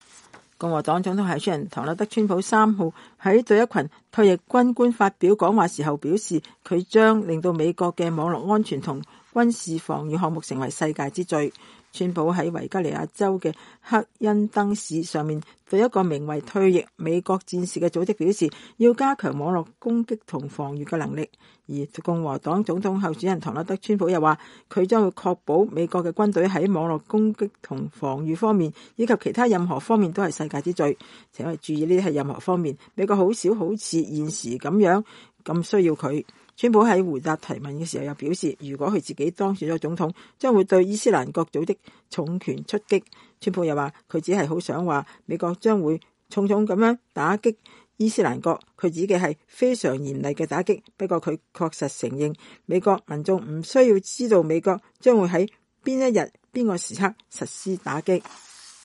川普3日在維吉尼亞州赫恩登市上對一個名為“退役美國戰士”的組織發表講話。